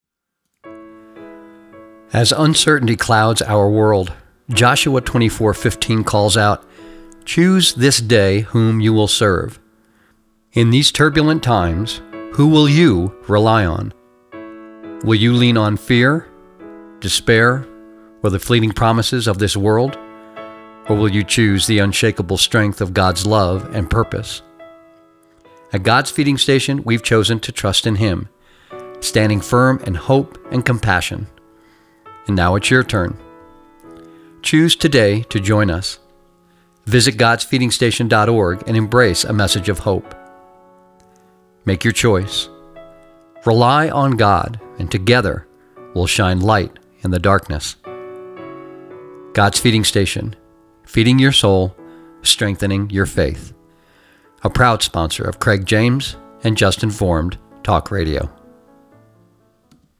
AM Radio Ad
English - Midwestern U.S. English
Middle Aged
Senior